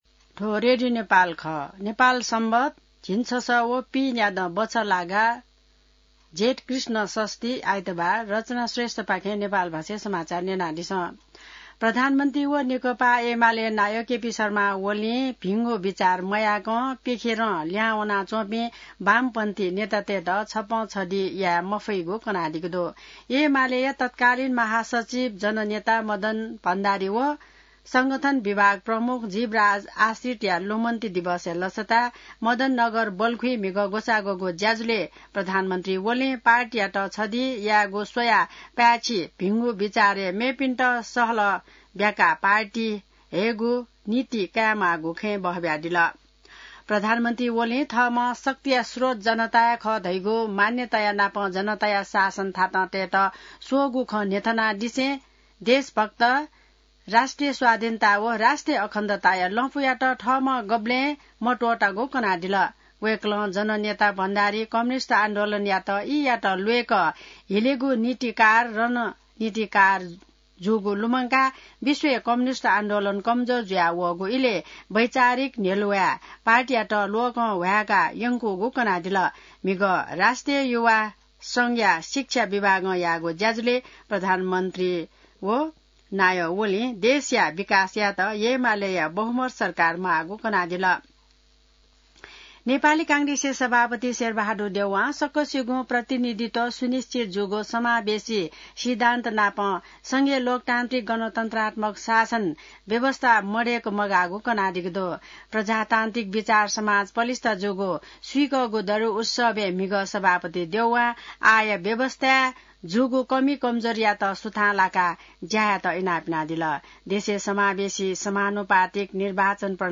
नेपाल भाषामा समाचार : ४ जेठ , २०८२